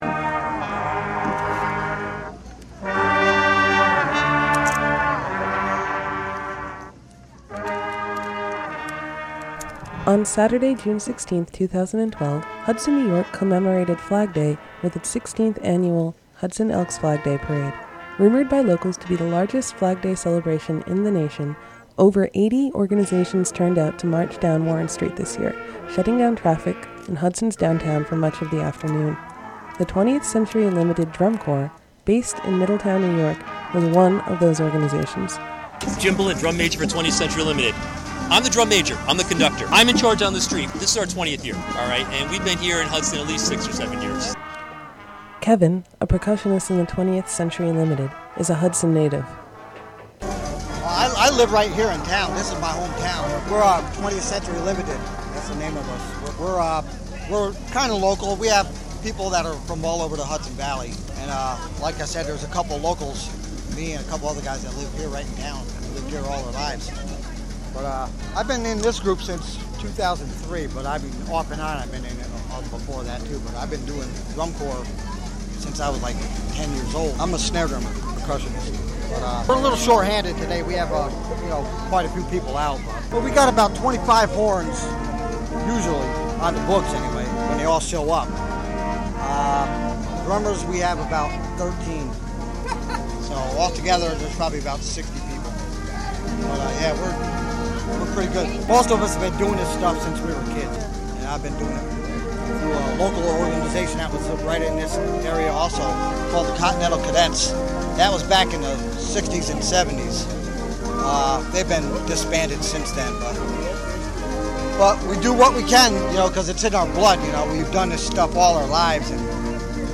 Live from Flag Day: Jun 09, 2012: 2pm - 3pm